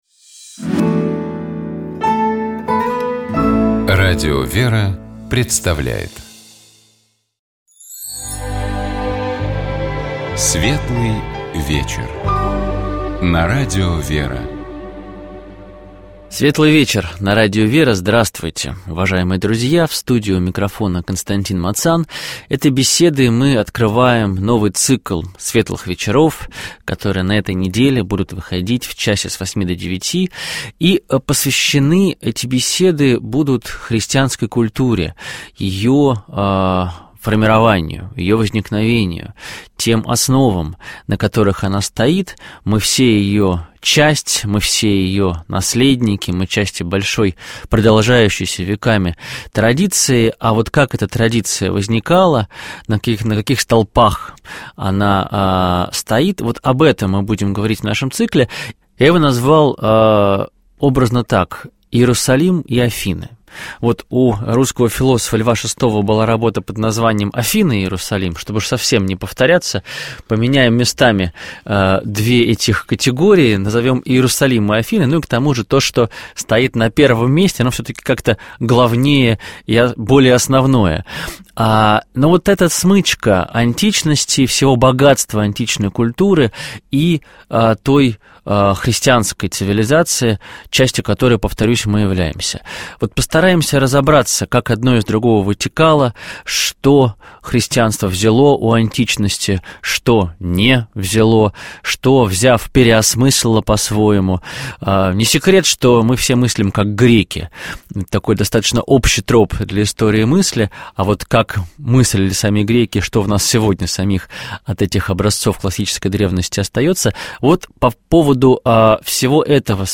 У нас в гостях был сотрудник Учебного комитета